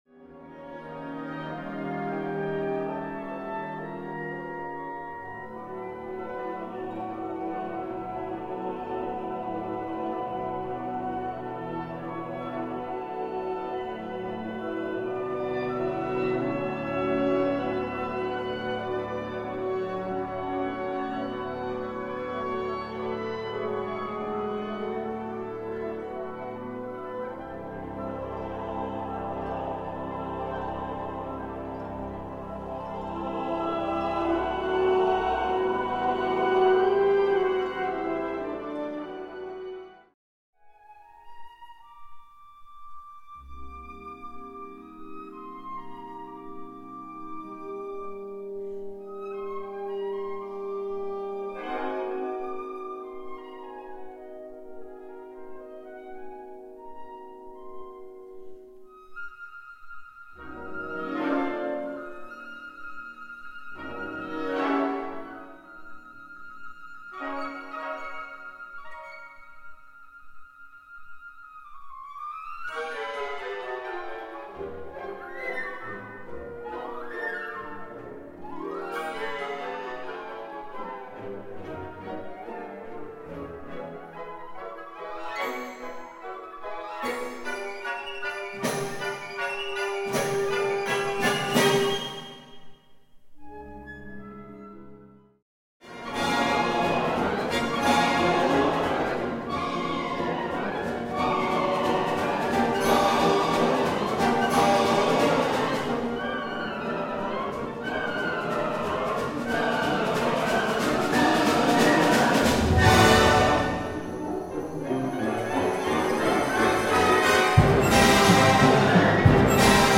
Key: All movements in original key